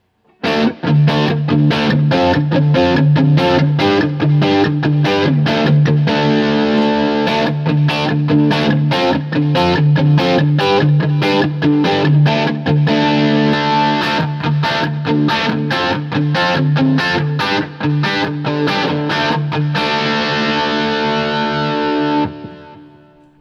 Every sound sample cycles from the neck pickup, to both, to the bridge pickup.
D-Shape Chords
[/dropshadowbox]For these recordings I used my normal Axe-FX Ultra setup through the QSC K12 speaker recorded into my trusty Olympus LS-10.
2000-Guild-Starfire4-D-Shape-Backline.wav